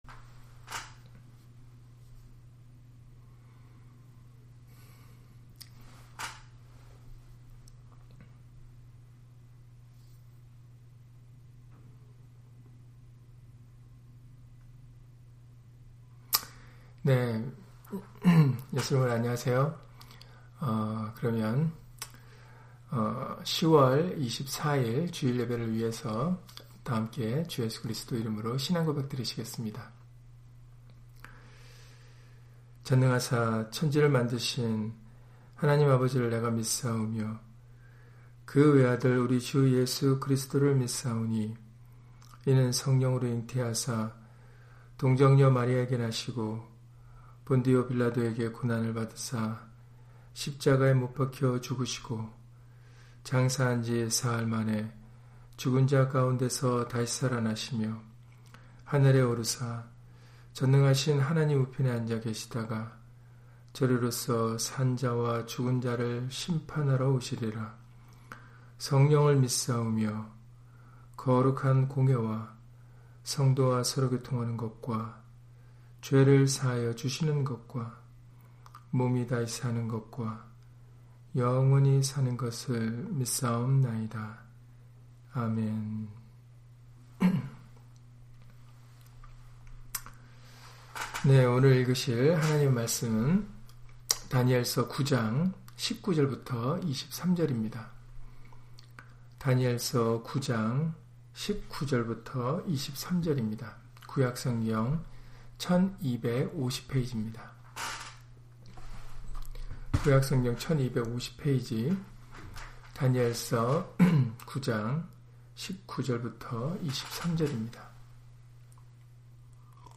다니엘 9장 19-23절 [너는 크게 은총을 입은 자라] - 주일/수요예배 설교 - 주 예수 그리스도 이름 예배당